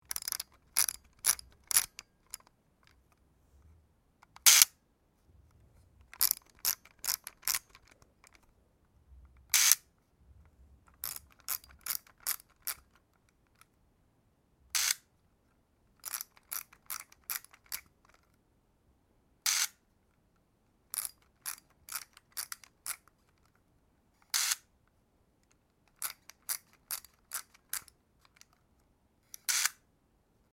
35mm Camera - Lomo Action Sampler